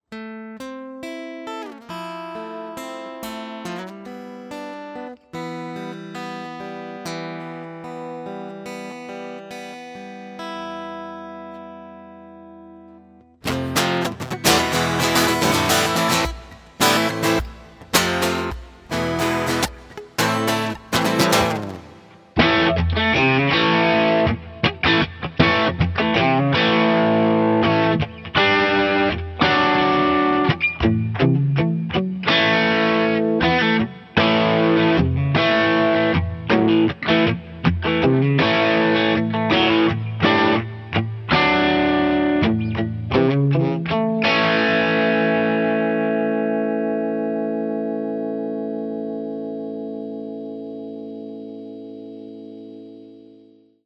hier eine kurze Aufnahme, erst der Piezo-Sound, dann der Steak-Humbucker.
PRS SE Custom 24 Semi-Hollow Piezo Snippets